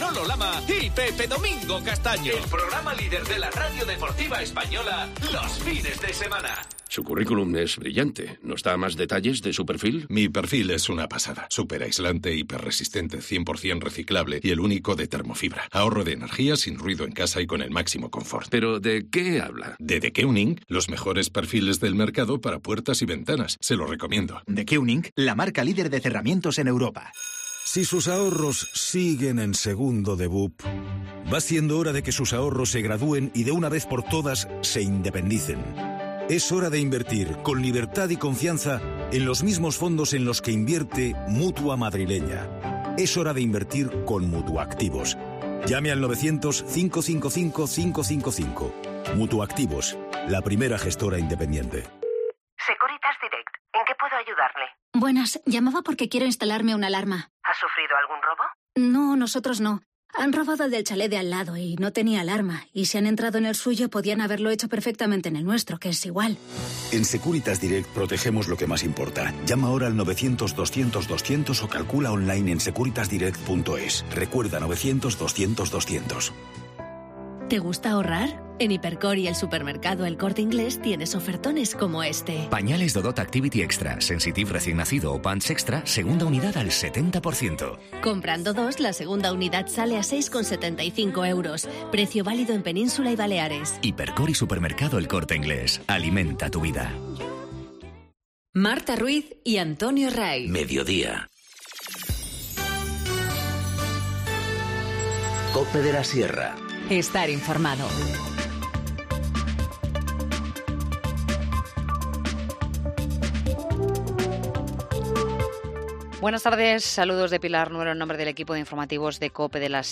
Informativo Mediodía 31 mayo 14:20h